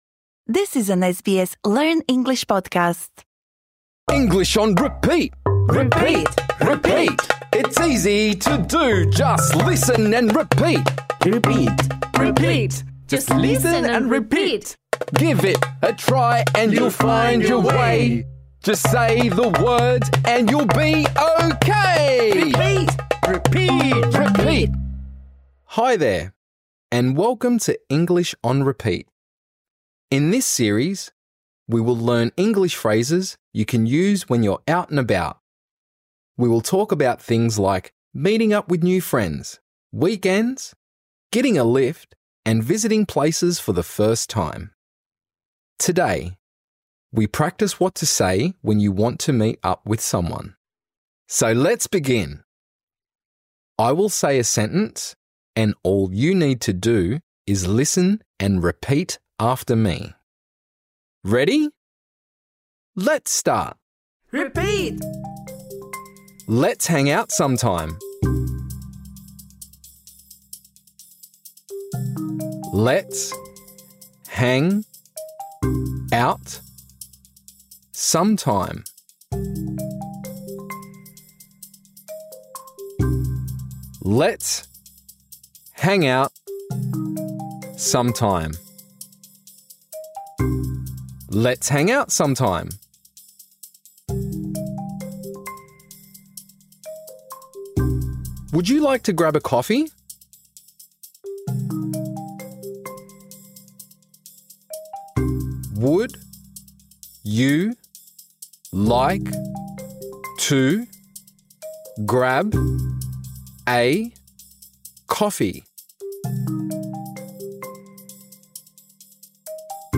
This lesson is designed for easy-level learners. In this episode, we practise saying the following phrases: Let’s hang out sometime.